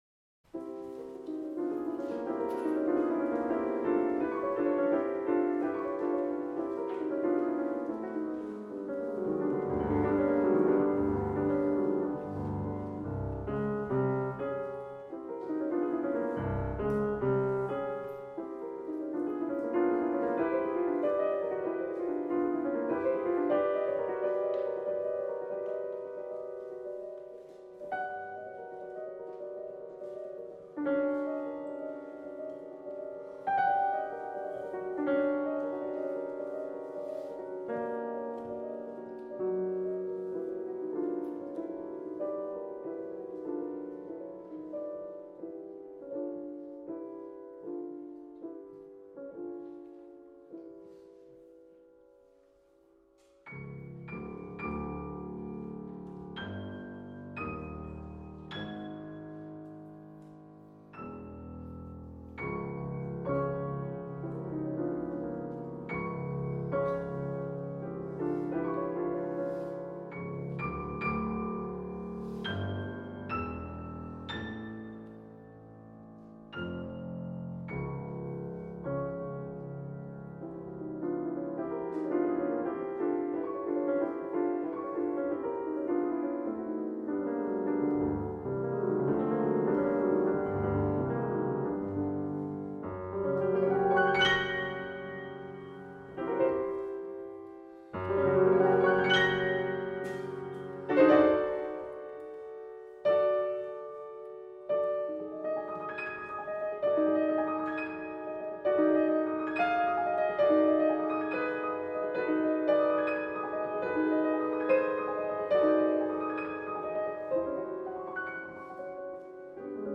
Piano
piano
Style: Classical
Audio: Boston - Isabella Stewart Gardner Museum